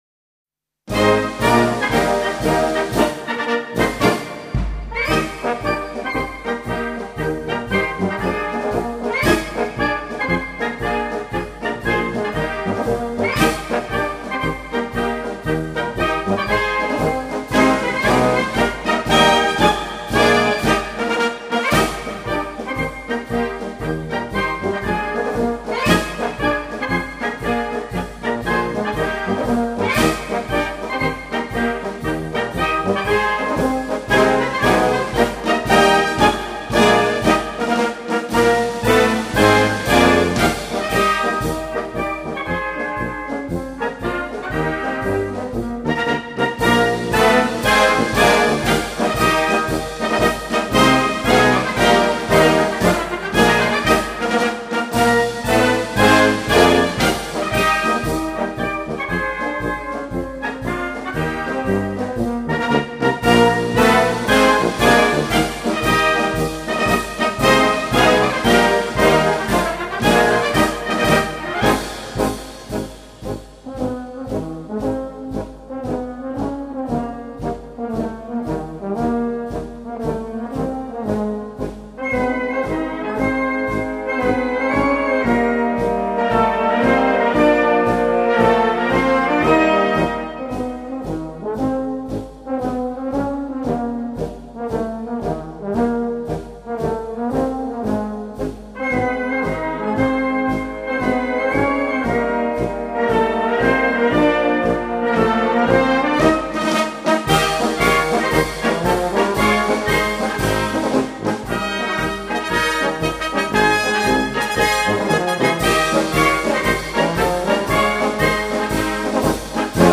Ein wirksamer Marsch für Ihr Konzert.